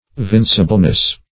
Vincibleness \Vin"ci*ble*ness\, n. The quality or state of being vincible.